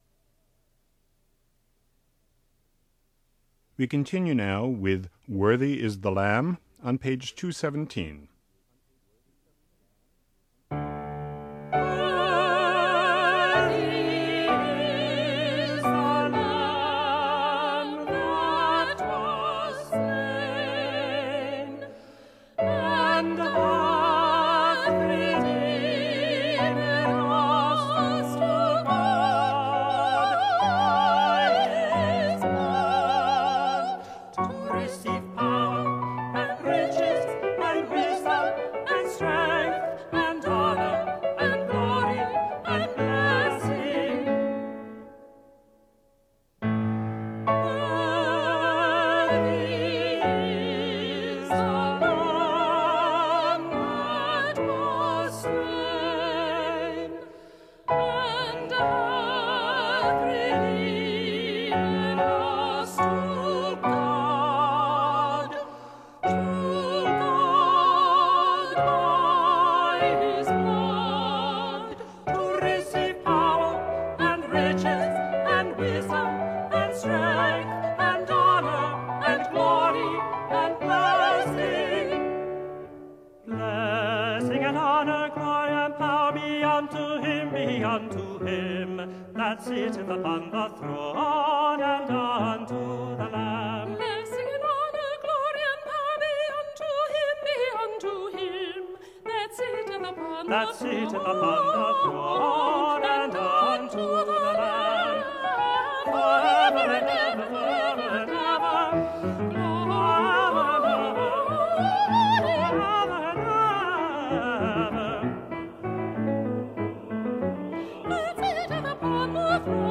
They are divided into two sections with either Alto and Bass together  or  Soprano and Tenor – since in each case the parts are well separated and tonally different you should be able to hear your part fairly clearly.
Soprano/Tenor